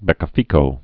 (bĕkə-fēkō)